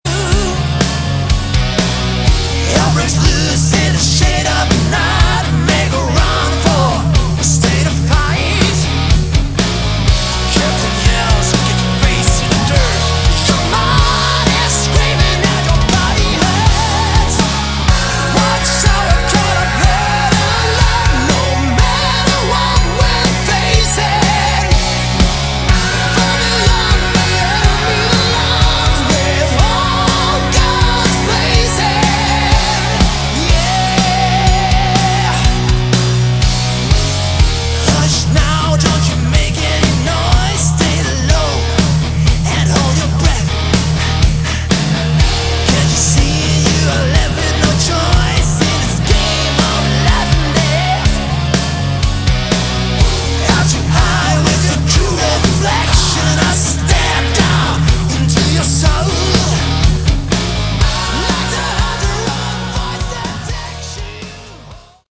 Category: Melodic Rock
guitars, keyboards
lead vocals, guitars
drums
bass
saxophone
backing vocals